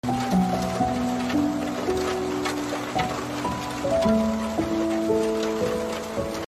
Relaxing Rain and Fireplace Crackling sound effects free download